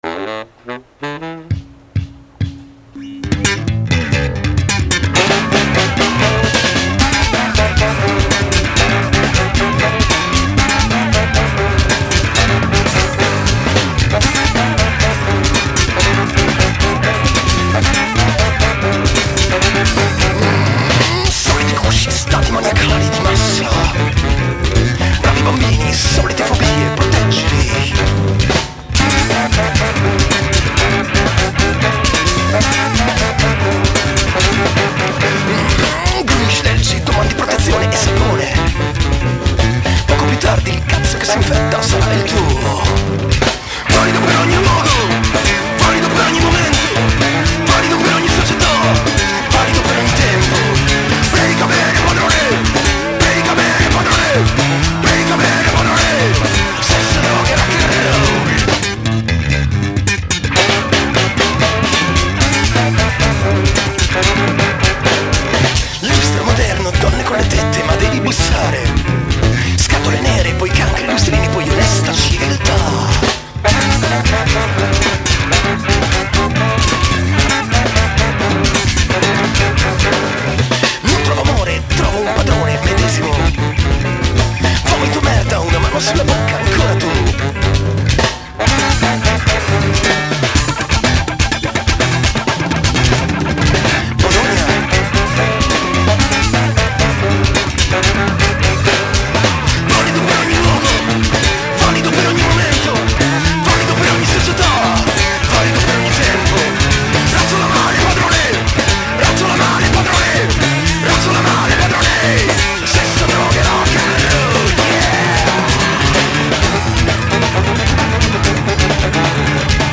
basso & voce
chitarra
sax baritono & flauto
batteria